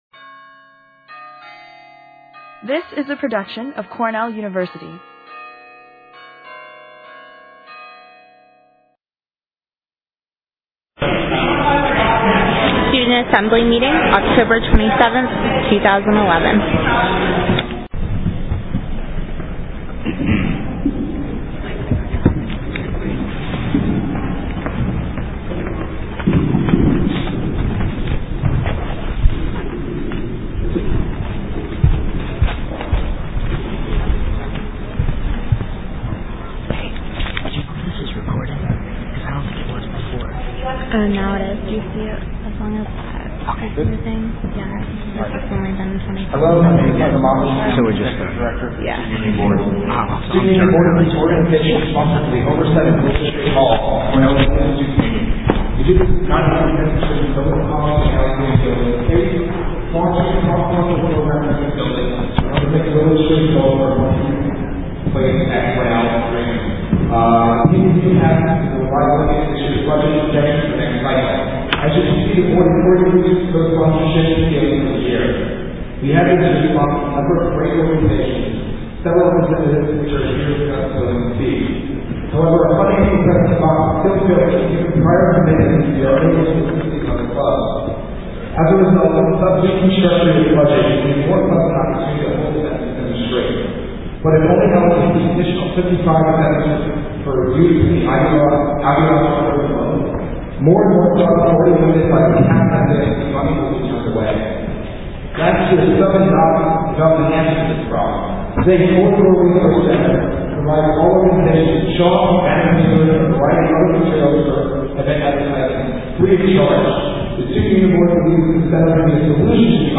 Agenda Audio First five minutes missing due to technical difficulties.